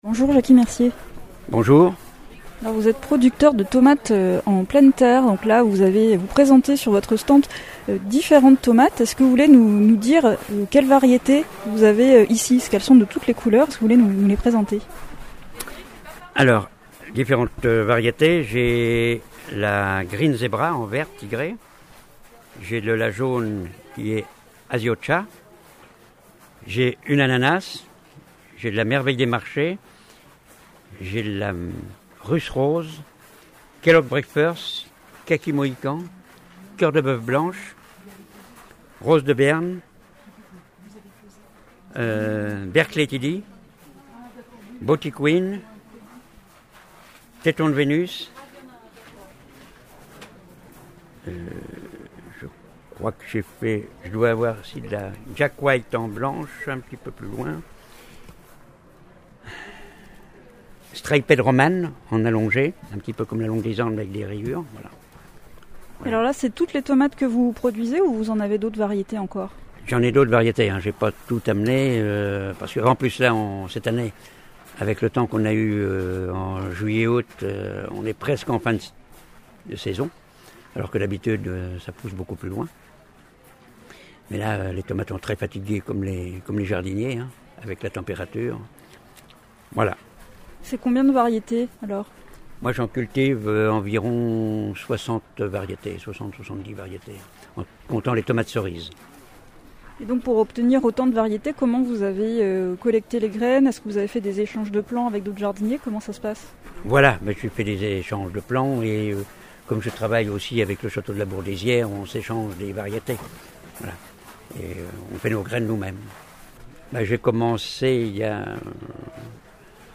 Direction Montlouis-sur-Loire, Château de la Bourdaisière, pour le 24e festival de la tomate et des saveurs.